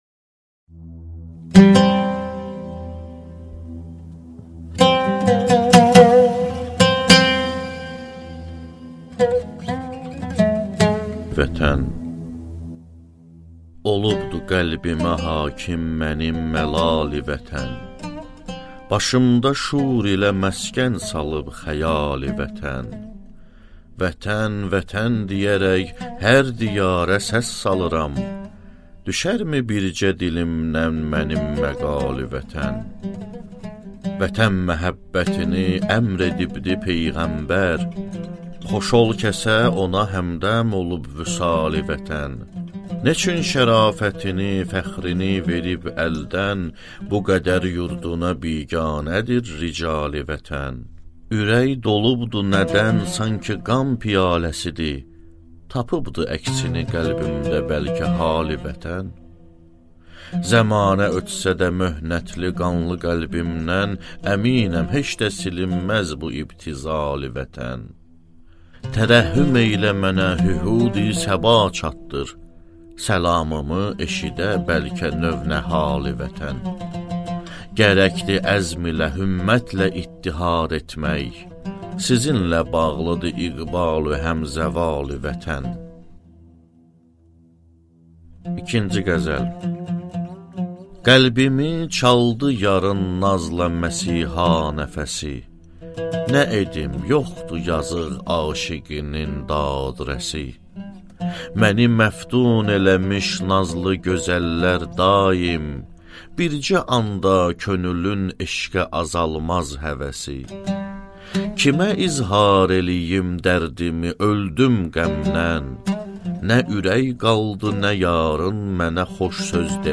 Аудиокнига Hüseyn Cavidin lirikası - (140 il) | Библиотека аудиокниг